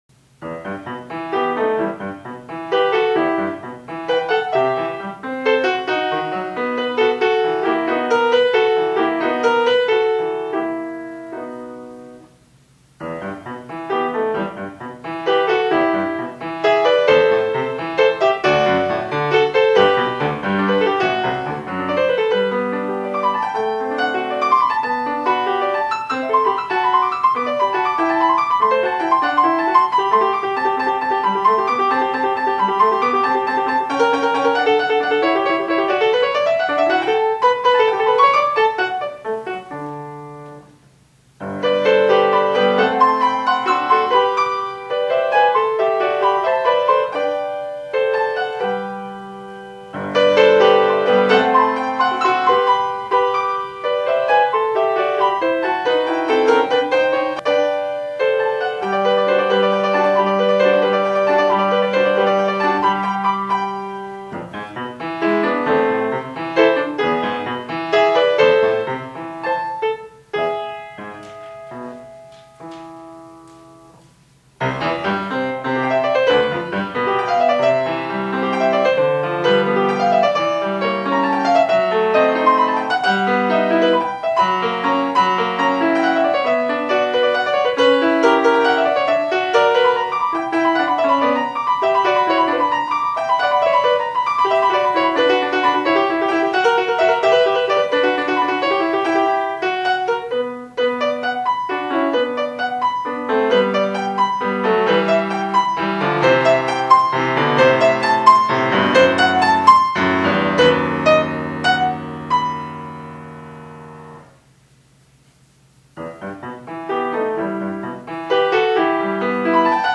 Listen to the Sonata in E Minor 1st Movement here: You can download this as an MP3 here .